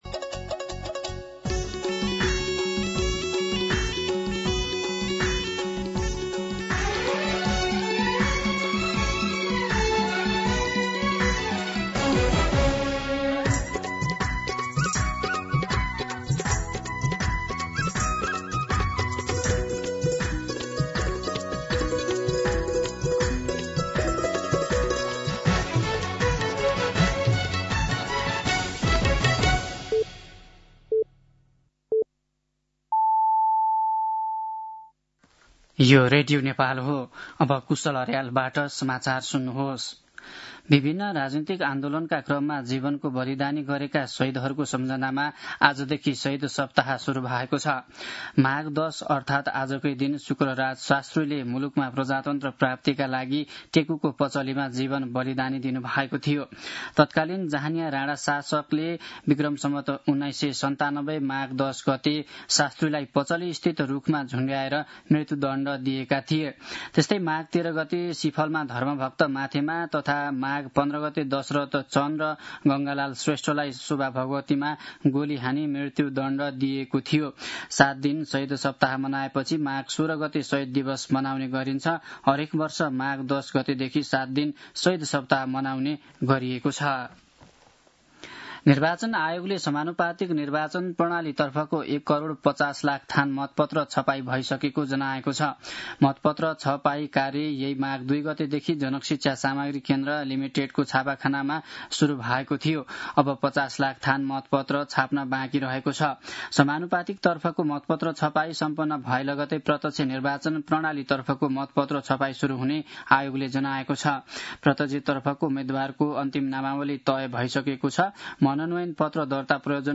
दिउँसो १ बजेको नेपाली समाचार : १० माघ , २०८२